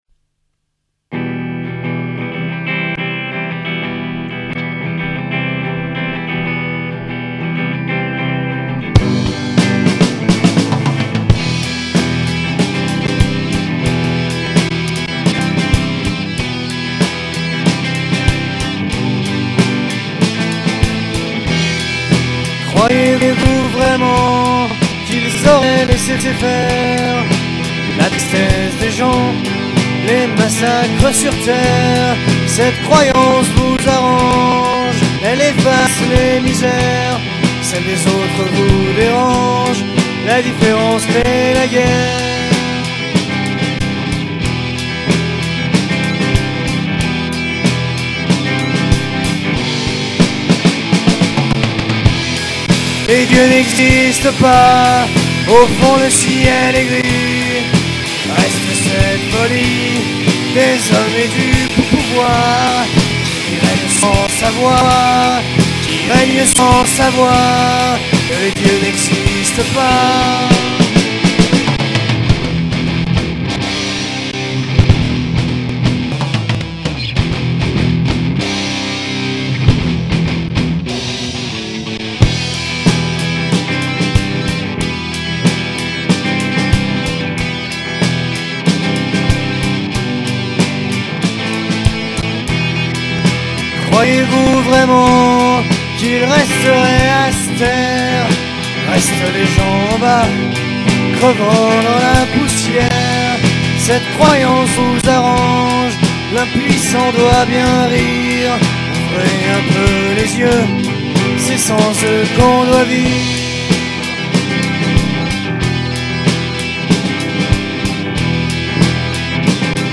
guitare
basse
batterie